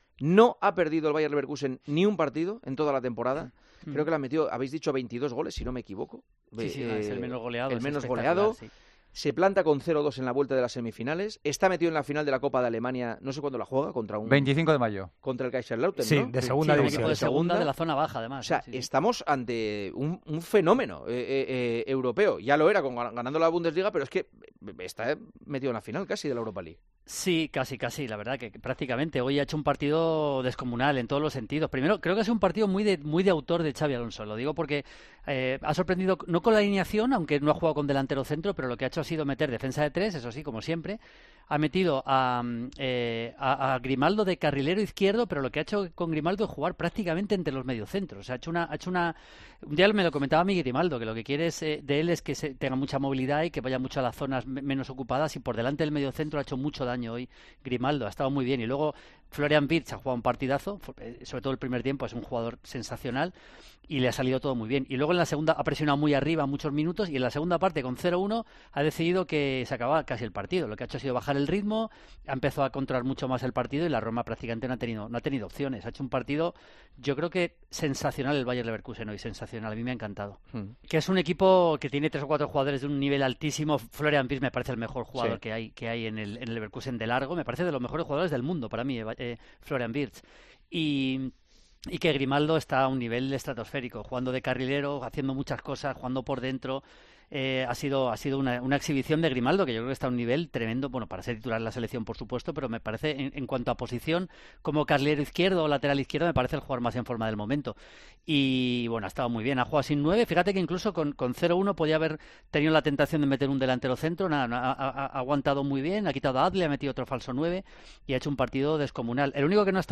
El director del programa y el experto en fútbol internacional analizan en El Partidazo de COPE el efecto que está consiguiendo el entrenador español en Alemania y Europa